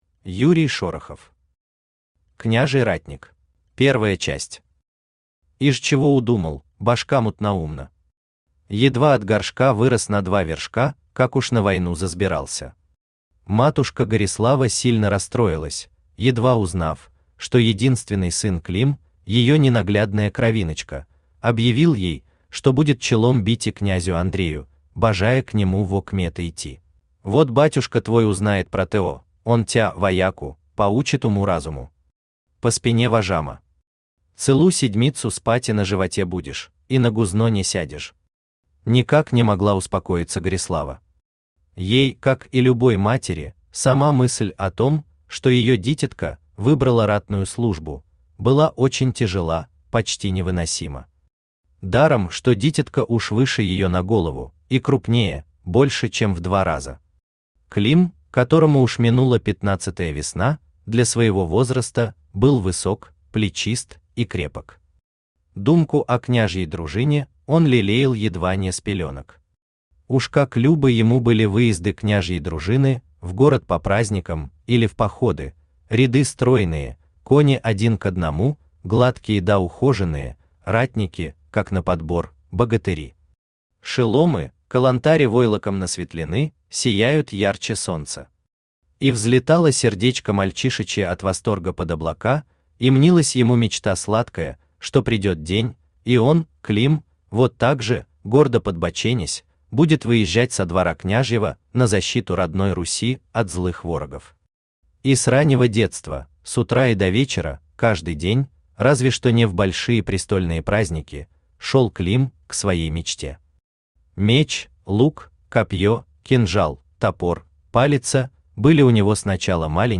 Aудиокнига Княжий ратник Автор Юрий Шорохов Читает аудиокнигу Авточтец ЛитРес.